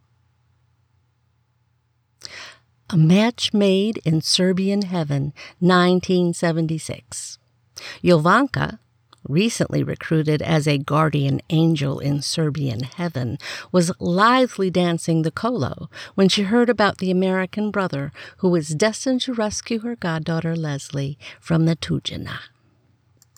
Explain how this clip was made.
I made a correction called “WoolSock.” It’s a plugin setting for Effect > Equalizer. The second one should be less “essy.”